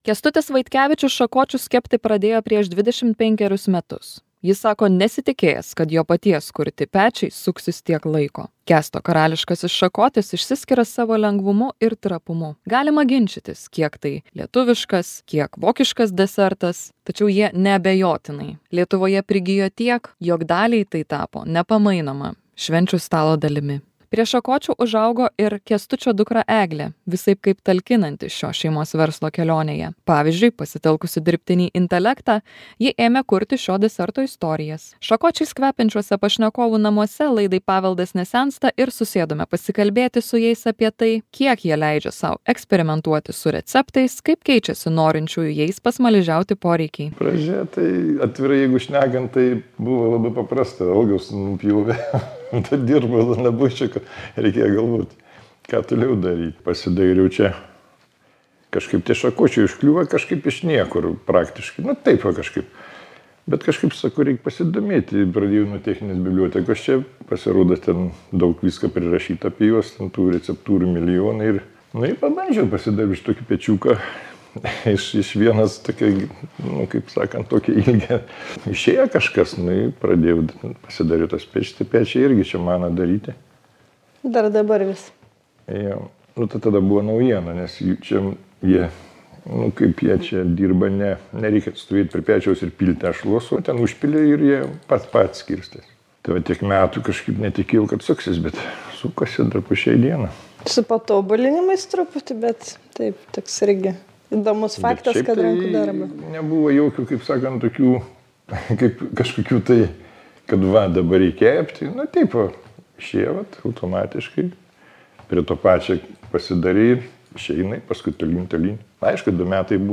Šakočiais kvepiančiuose pašnekovų namuose laidai „Paveldas nesensta“ susėdome pasikalbėti apie tai, kiek jie leidžia sau eksperimentuoti su receptais, kaip keičiasi norinčiųjų jais pasmaližiauti poreikiai.